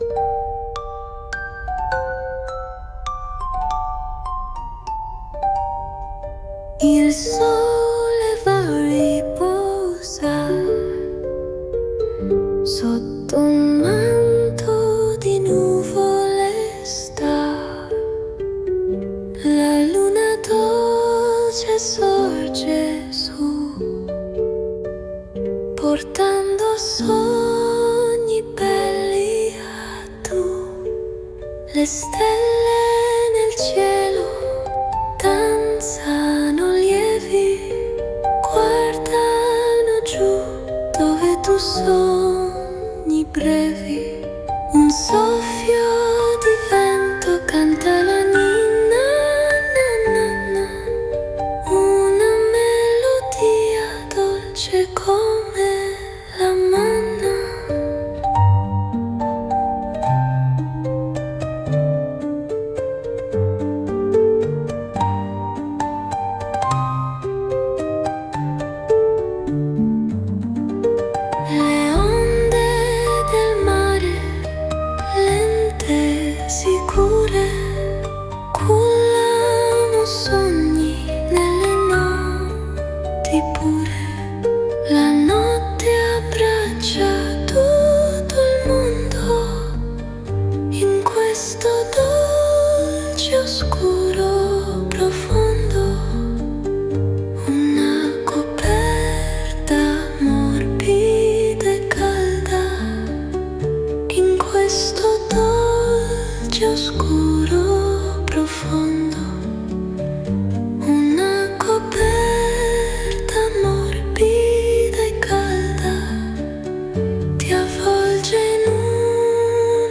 🎵 Nanna📺 GUARDA